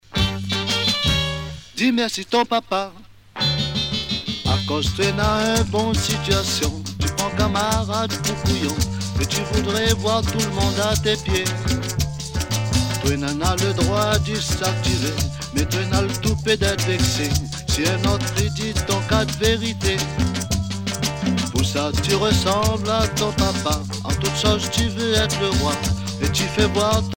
danse : séga
Pièce musicale éditée